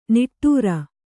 ♪ niṭṭūra